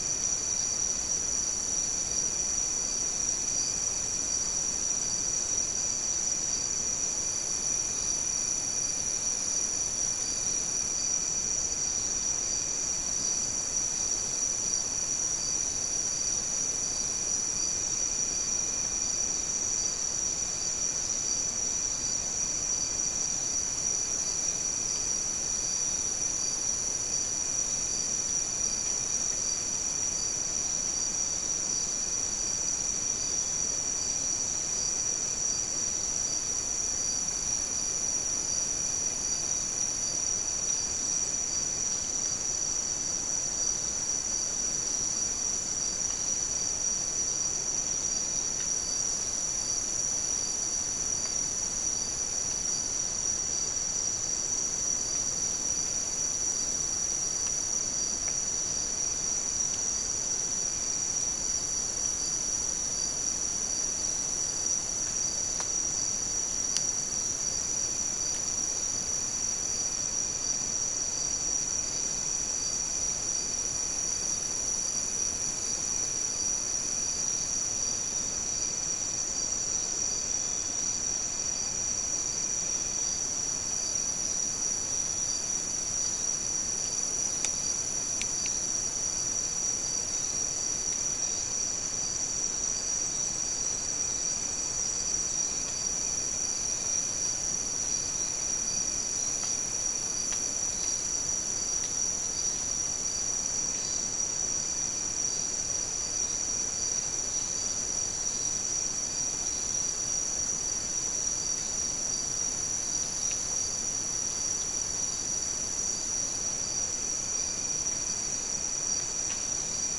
Soundscape
South America: Guyana: Rock Landing: 1
Recorder: SM3